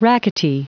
Prononciation du mot rackety en anglais (fichier audio)
Prononciation du mot : rackety